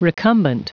Prononciation du mot recumbent en anglais (fichier audio)
Prononciation du mot : recumbent